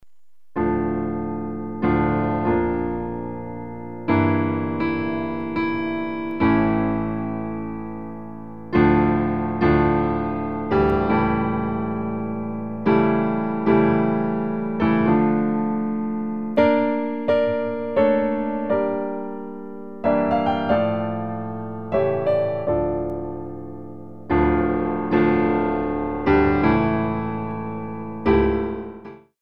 Piano Arrangements of Classical Compositions